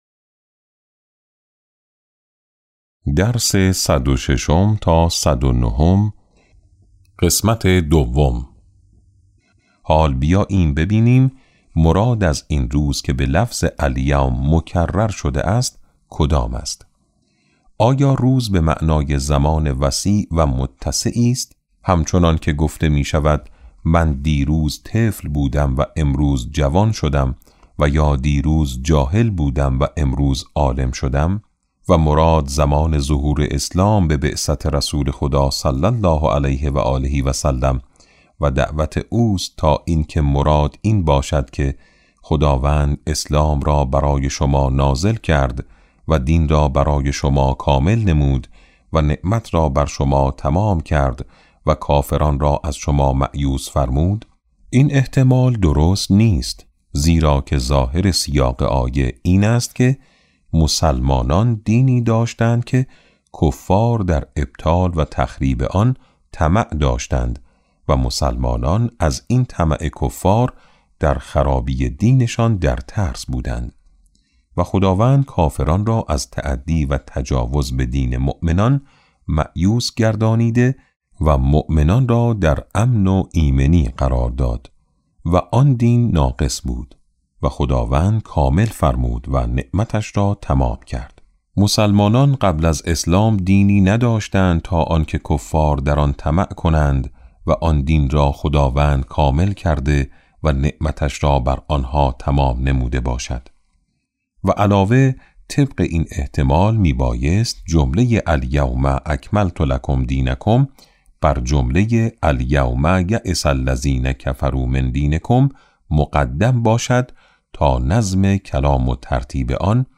کتاب صوتی امام شناسی ج۸ - جلسه2